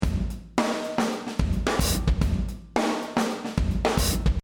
Sequenced drums